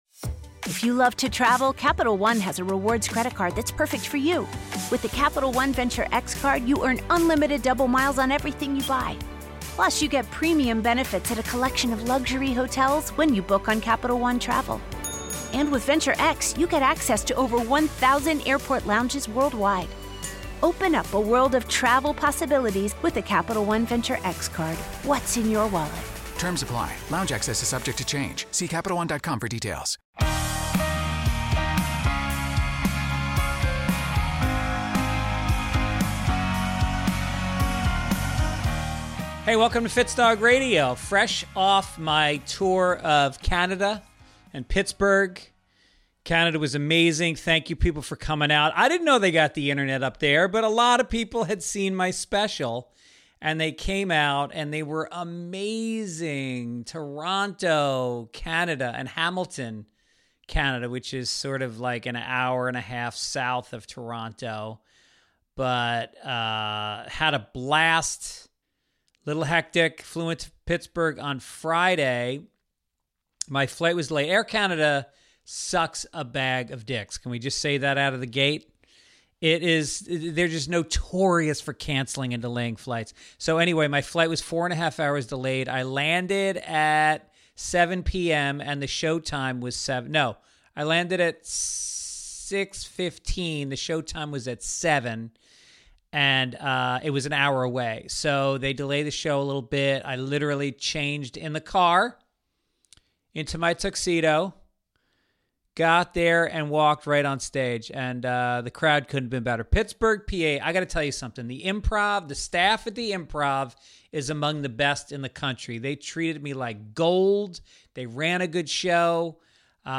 Honest funny interviews w/ Greg Fitzsimmons' new and old friends diving deep and laughing hard.